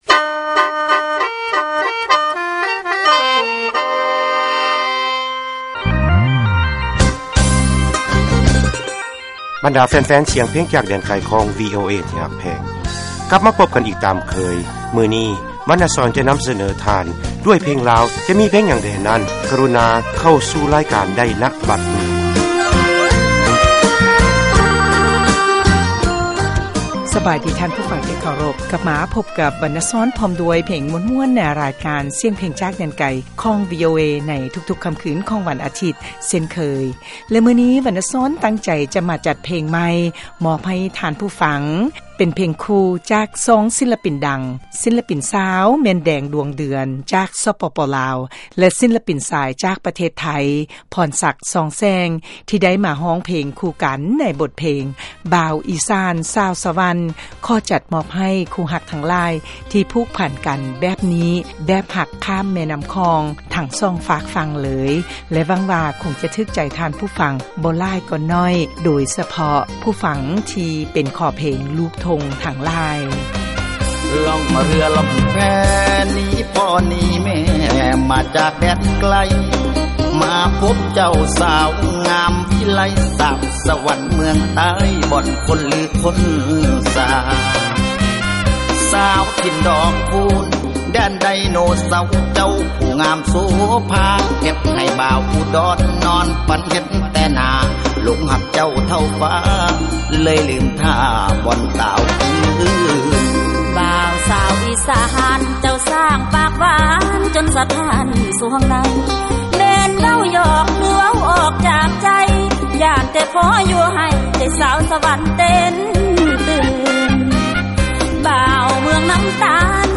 ມາຟັງເພງຄູ່ ລາວໄທ